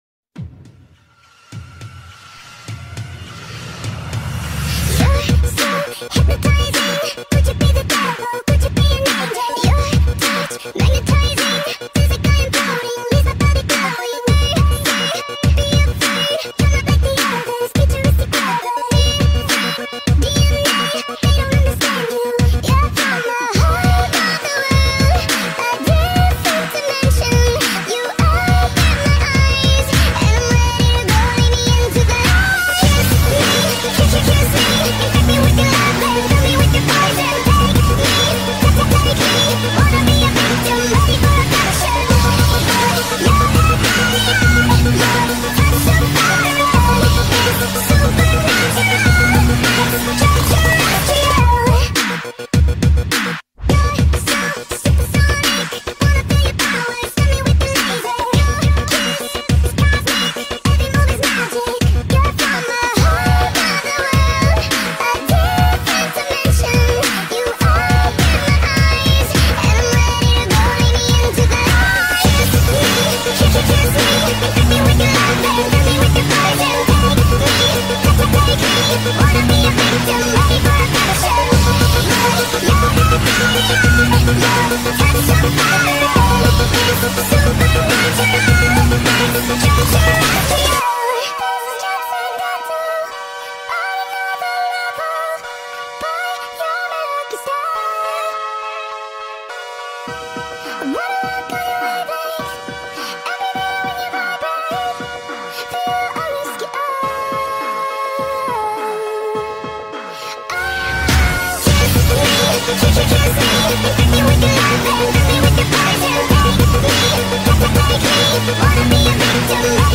فانک🔥